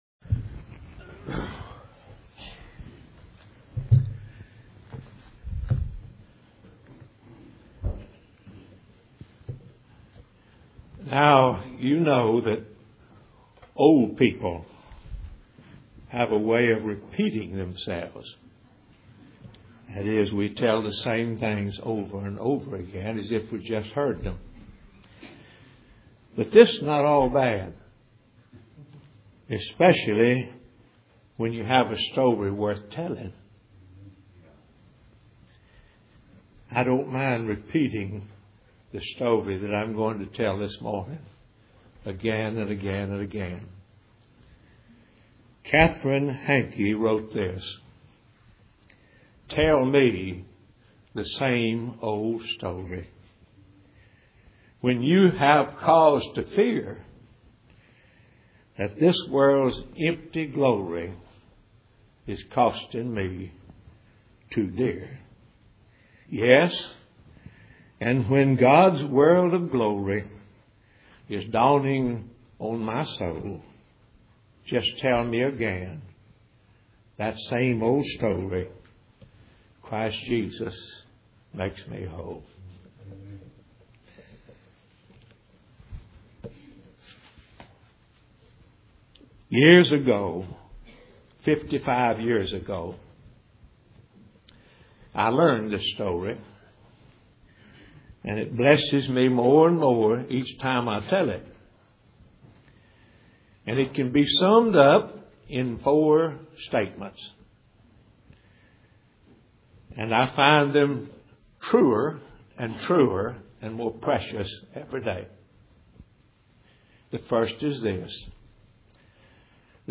God's Mercies to Sinners | SermonAudio Broadcaster is Live View the Live Stream Share this sermon Disabled by adblocker Copy URL Copied!